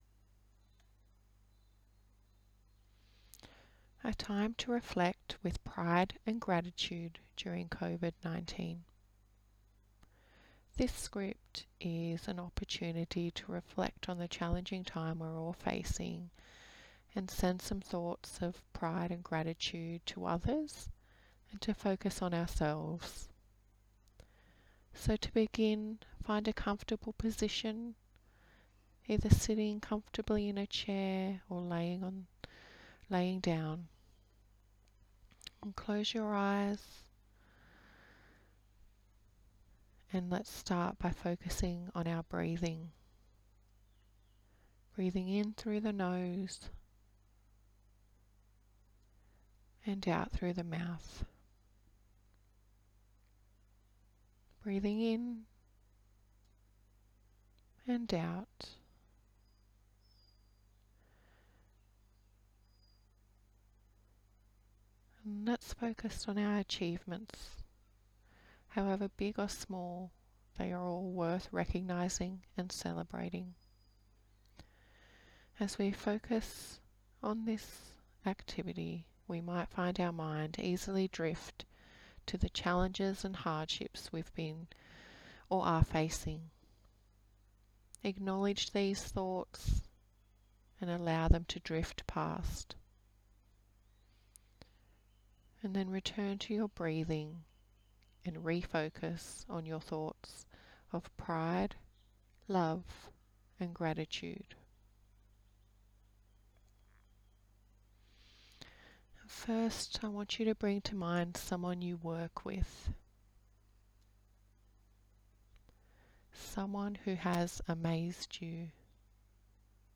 Also find here an additional mindfulness reflection exercise 'A time to reflect with pride, love & gratitude during COVID-19'.